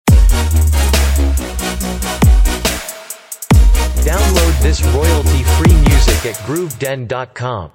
Energetic elegant opening logo for any of your projects.